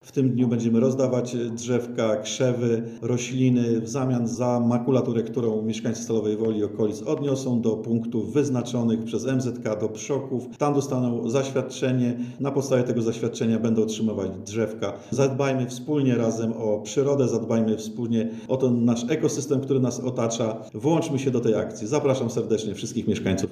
Do wzięcia udziału w akcji zachęca wicestarosta stalowowolski Stanisław Sobieraj.